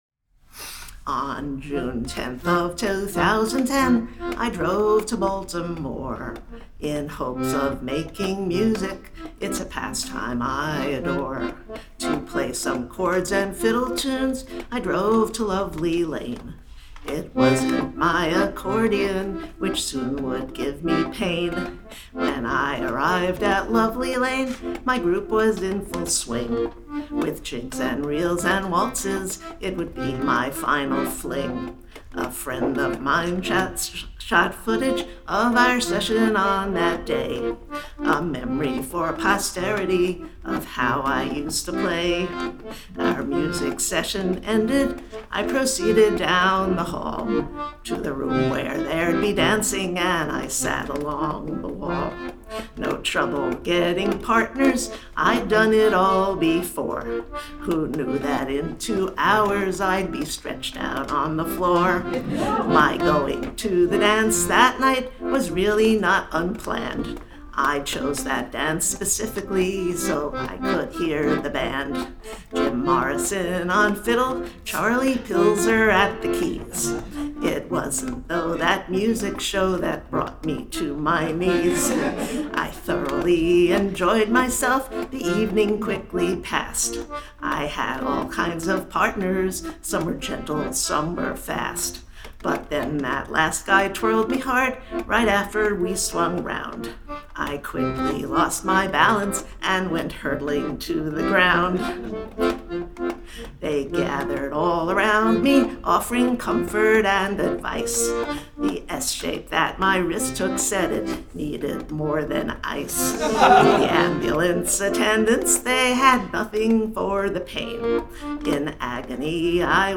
2025 Concert Recordings  - Wisdom House, Litchfield, CT